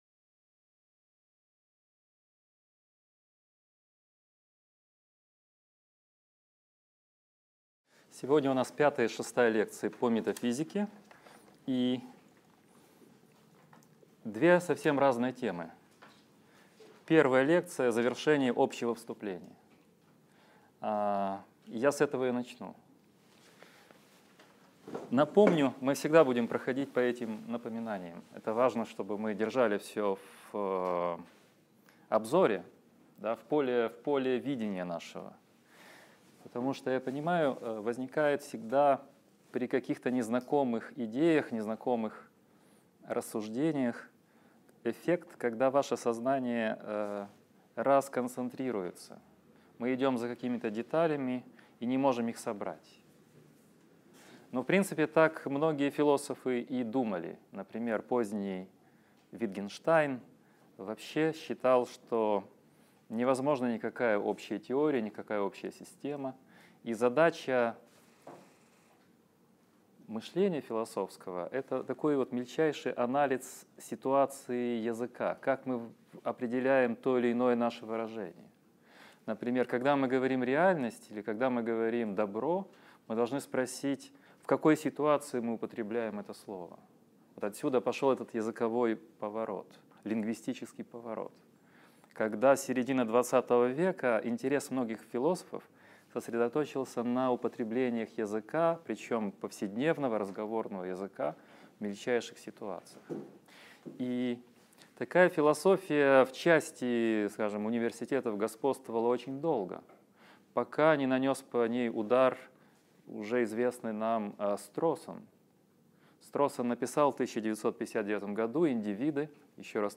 Аудиокнига Лекция 5. Логический позитивизм против метафизики | Библиотека аудиокниг